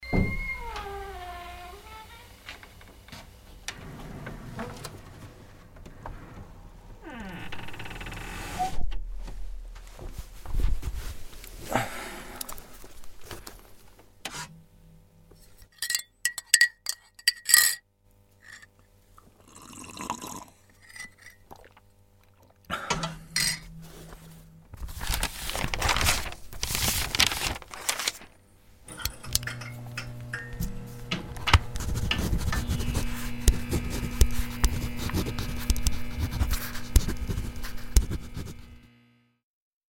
Žánr: Hip Hop/R&B